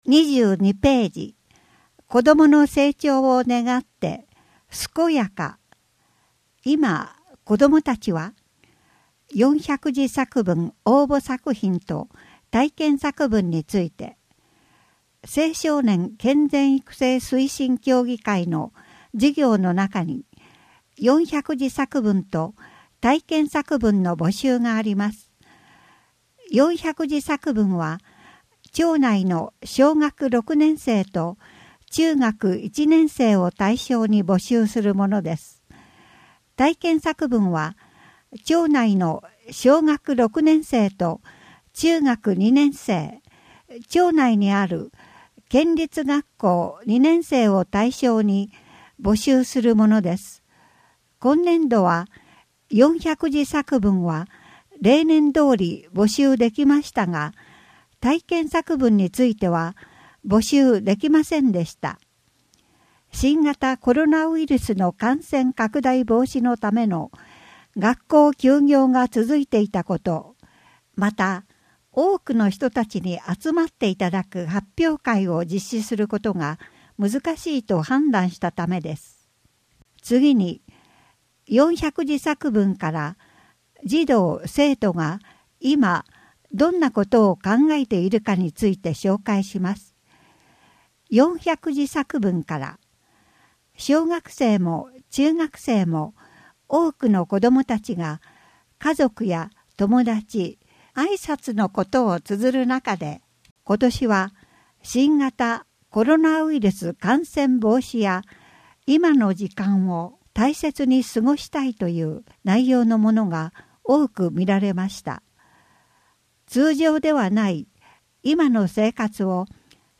音訳広報たわらもと